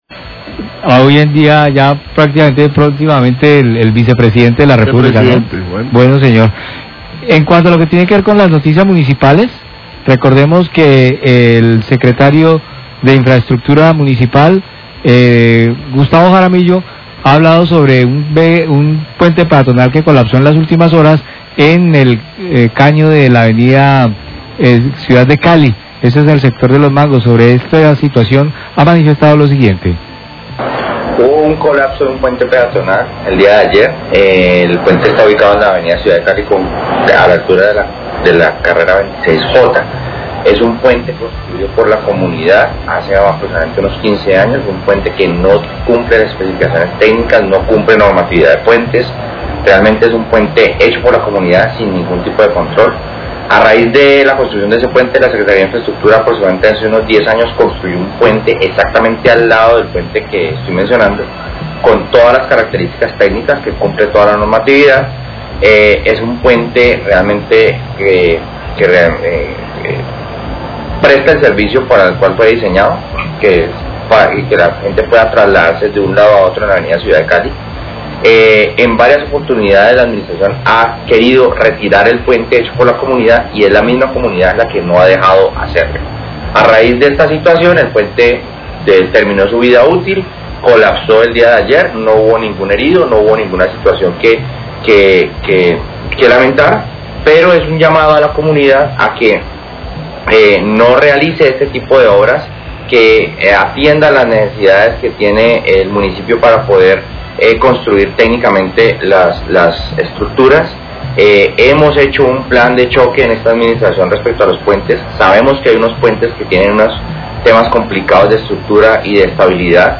SECRETARIO INFRAESTRUCTURA HABLÓ SOBRE LA CAÍDA DE PUENTE PEATONAL, RADIO CALIDAD, 12.50pm